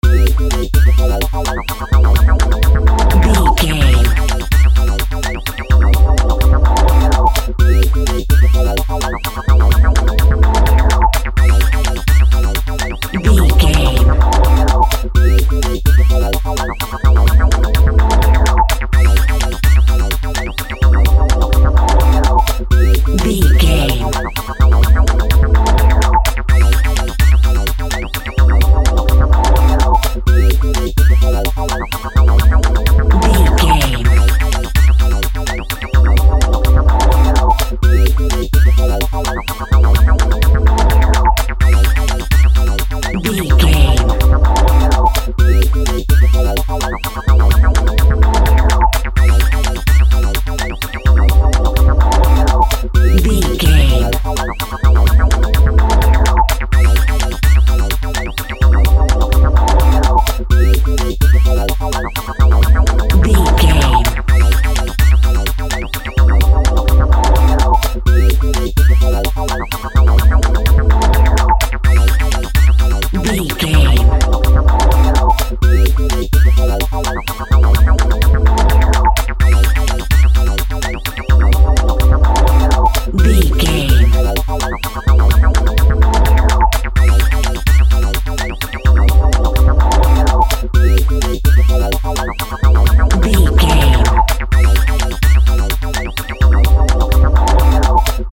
Playful Electronic Music.
Fast paced
Aeolian/Minor
dark
futuristic
driving
energetic
synthesiser
drum machine
Drum and bass
break beat
sub bass
synth lead